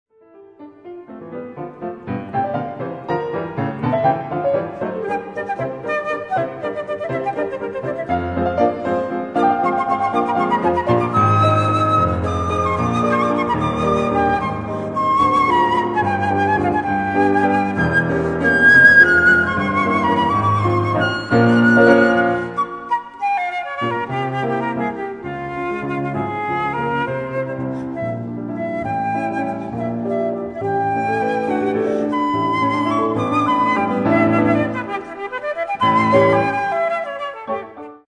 für Flöte und Klavier / for Flute and Piano